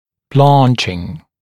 [‘blɑːnʧɪŋ][‘бла:нчин]побеление, побледнение